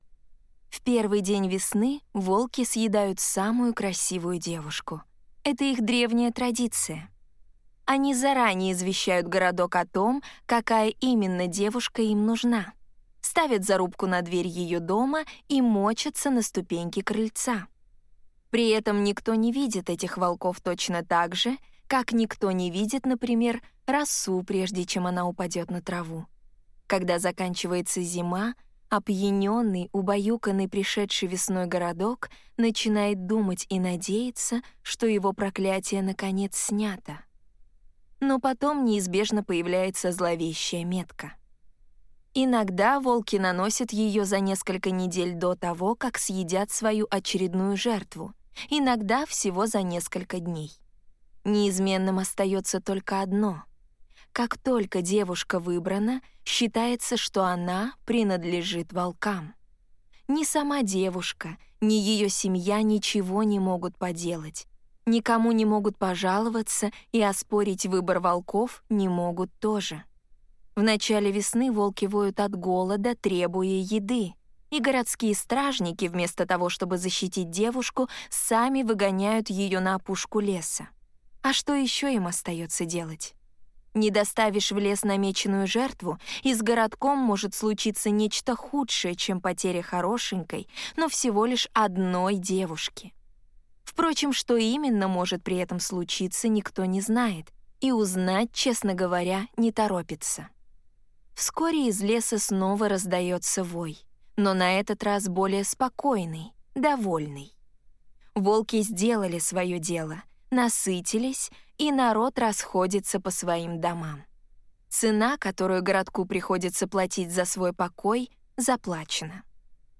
Аудиокнига Чудовища и красавицы. Опасные сказки | Библиотека аудиокниг